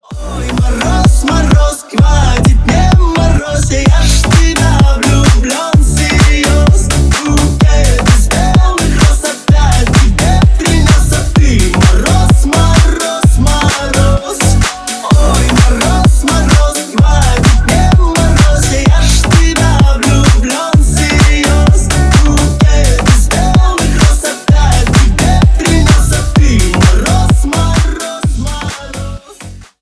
Ремикс
весёлые